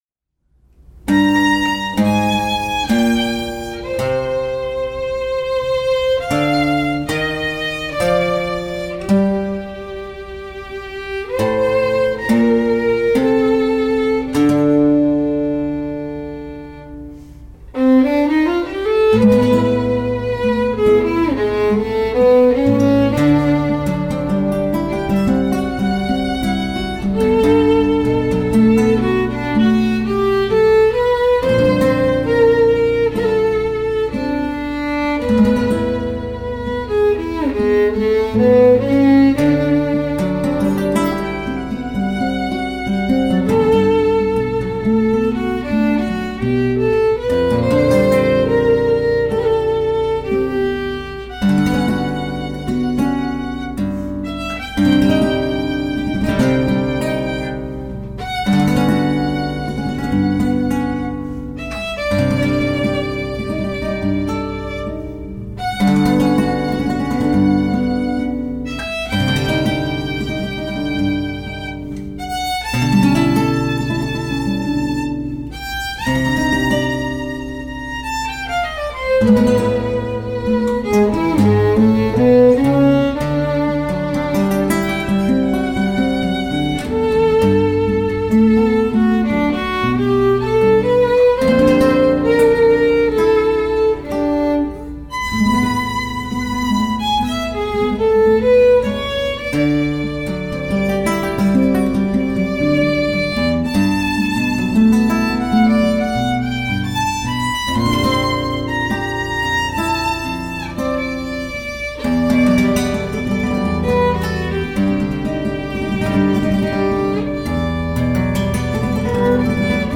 For Violin & Guitar
Guitar